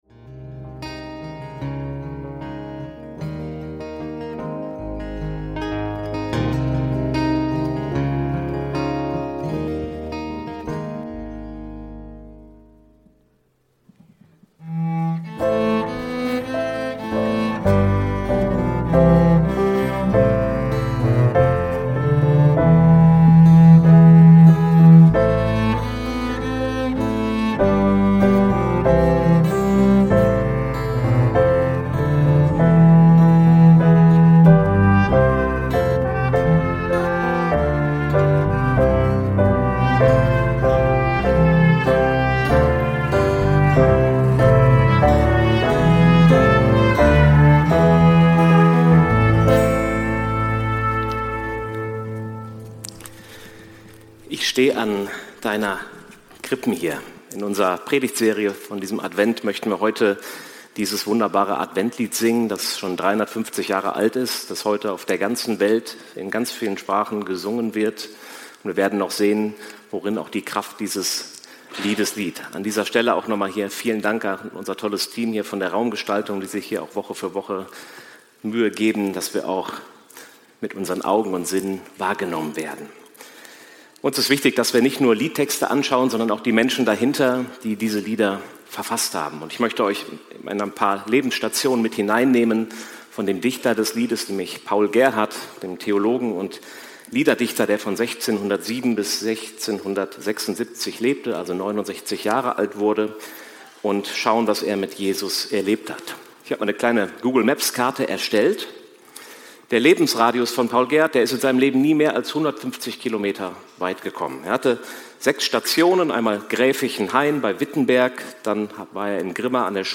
Ich steh an deiner Krippen hier – Predigt vom 21.12.2025